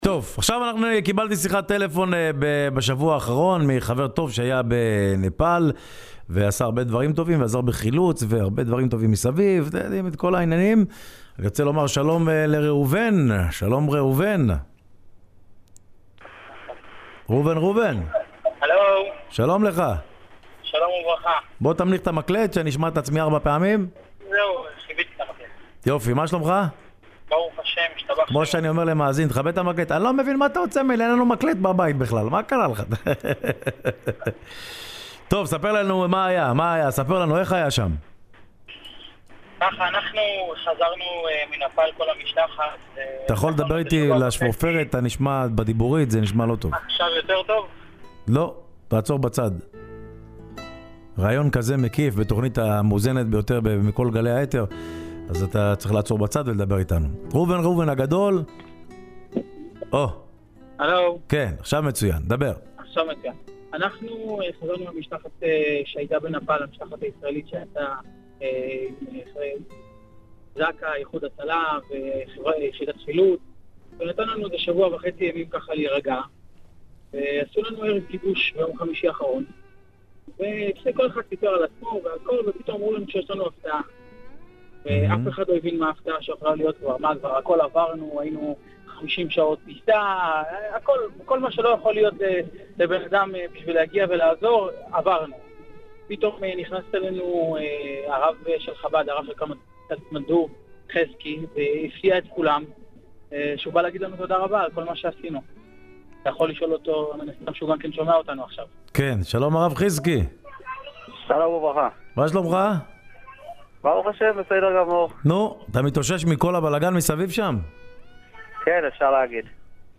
בשידור-חי